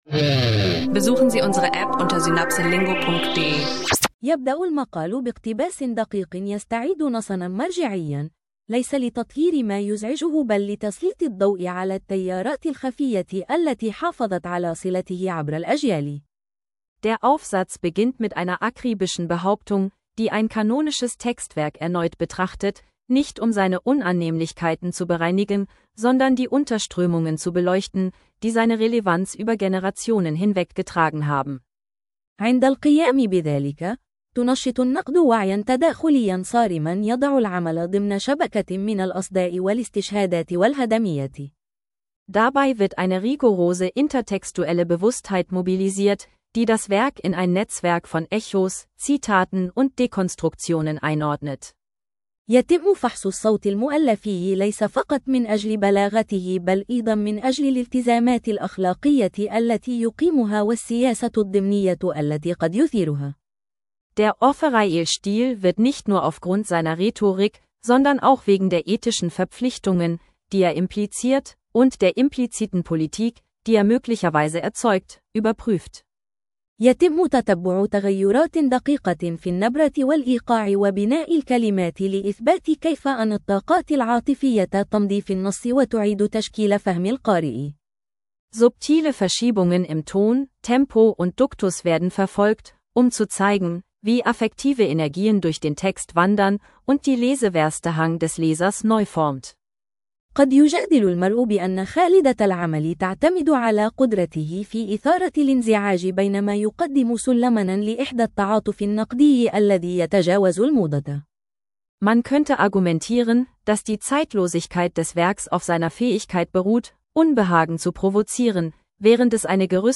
Eine KI-unterstützte, interaktive Arabisch-Lektionenreise: Kritische Literaturanalyse trifft Media Voices in Society